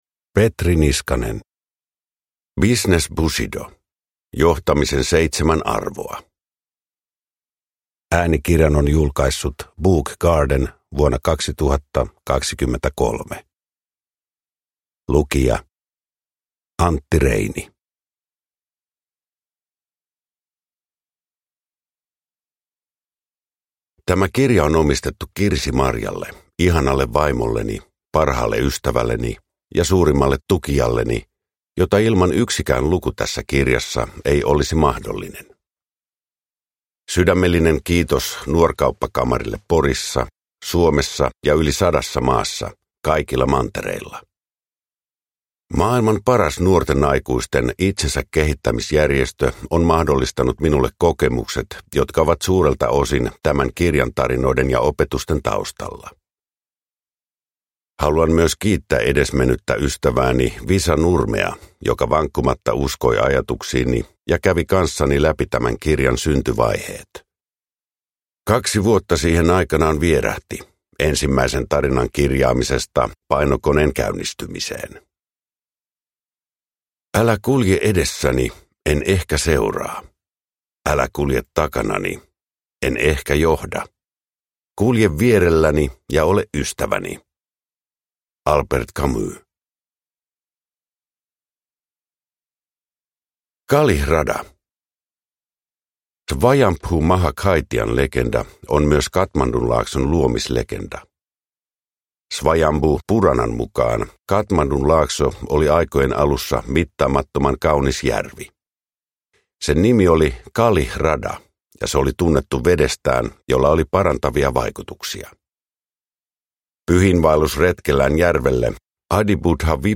Business Bushido – Ljudbok – Laddas ner
Uppläsare: Antti Reini